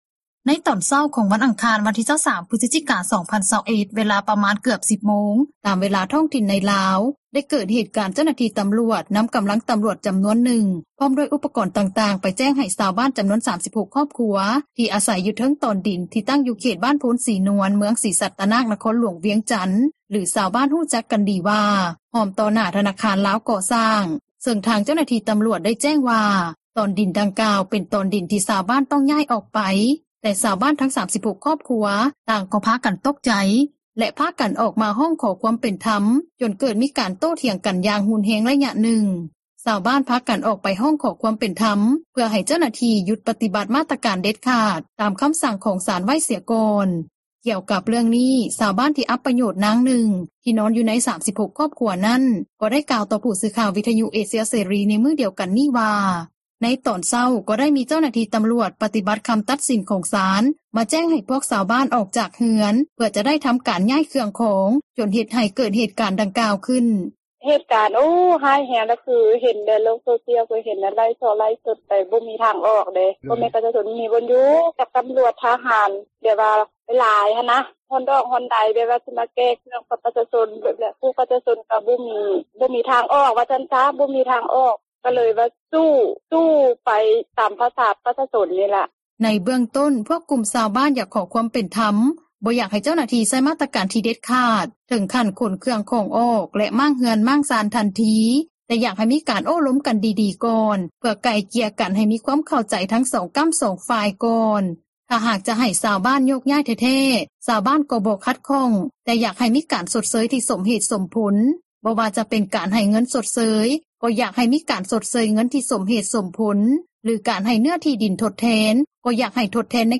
ກ່ຽວກັບເຣື່ອງນີ້, ຊາວບ້ານ ທີ່ອັບປໂຍດນາງນຶ່ງ ທີ່ນອນຢູ່ໃນ 36 ຄອບຄົວນັ້ນ, ກໍໄດ້ກ່າວຕໍ່ຜູ້ສື່ຂ່າວ ວິທຍຸເອເຊັຽເສຣີ ໃນມື້ດຽວກັນນີ້ວ່າ ໃນຕອນເຊົ້າ ກໍໄດ້ມີເຈົ້າໜ້າທີ່ຕຳຣວດ ປະຕິບັດຄຳຕັດສິນ ຂອງສານ ມາແຈ້ງໃຫ້ພວກຊາວບ້ານ ອອກຈາກເຮືອນ ເພື່ອຈະໄດ້ທຳການຍ້າຍເຄື່ອງຂອງ ຈົນເຮັດໃຫ້ເກີດເຫດການດັ່ງກ່າວຂຶ້ນ.